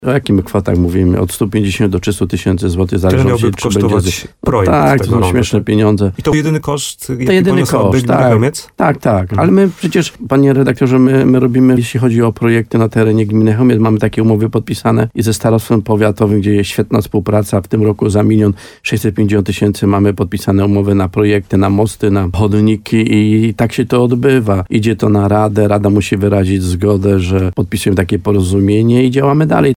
Rozmowa ze Stanisławem Kuzakiem: Tagi: wójt Dąbrowa Stanisław Kuzak Nowy Sącz Słowo za Słowo Gmina Chełmiec rondo Wielogłowy HOT